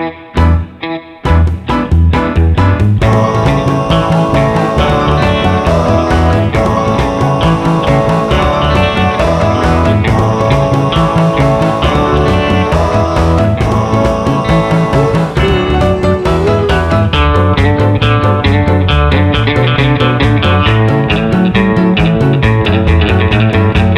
no Backing Vocals Rock 'n' Roll 2:27 Buy £1.50